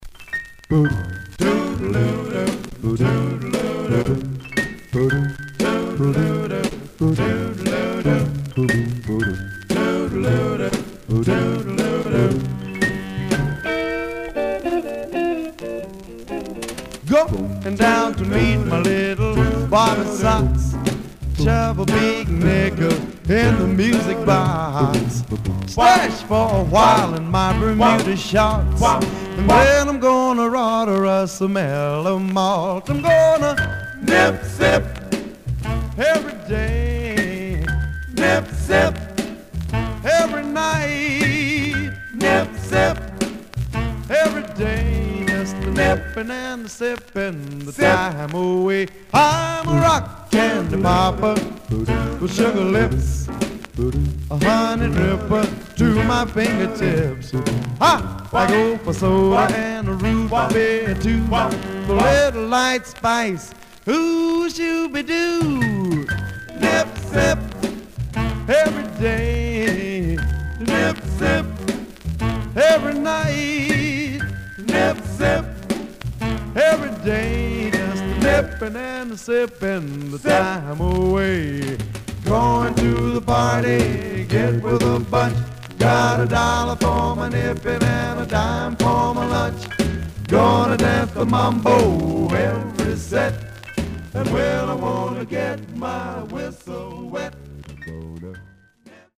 Some surface noise/wear
Mono
Male Black Group Condition